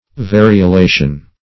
Variolation \Va`ri*o*la"tion\, n. (Med.)